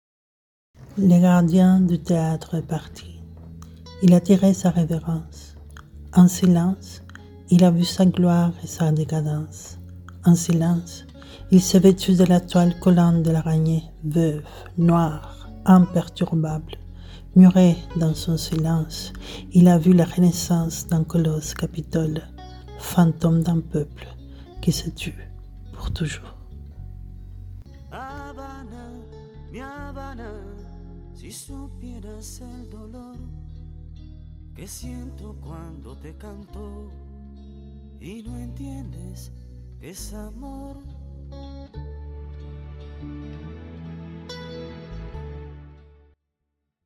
Audio Poèmes